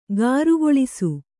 ♪ gārugoḷisu